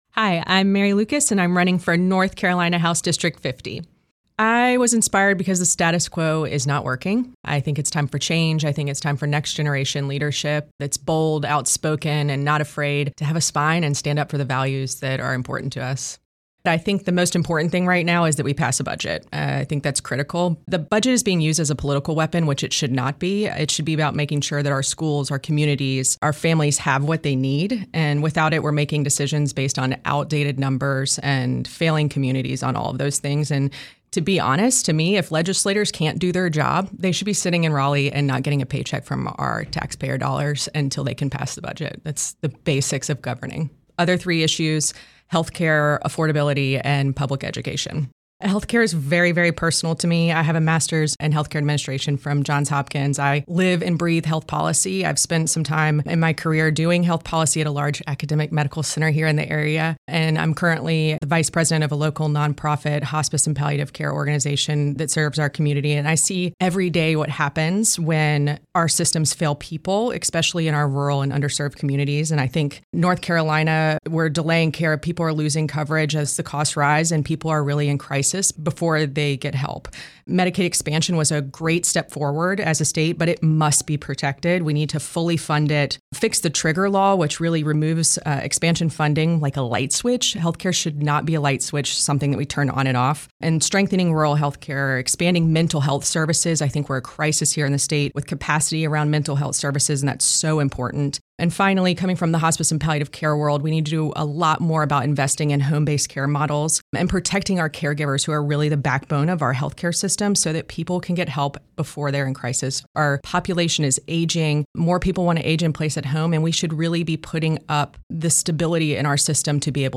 97.9 The Hill spoke with each candidate, asking these questions that are reflected in the recorded responses: